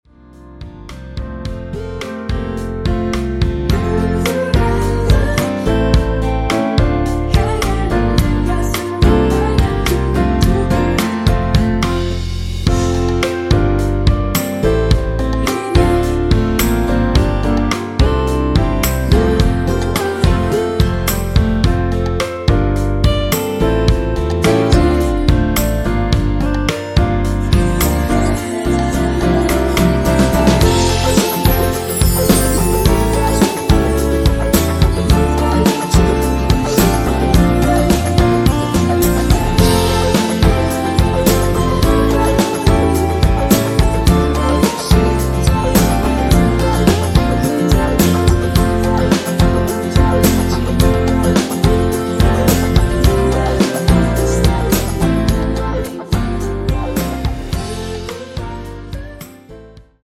원키에서(-2)내린 멜로디와 코러스 포함된 MR입니다.(미리듣기 확인)
앞부분30초, 뒷부분30초씩 편집해서 올려 드리고 있습니다.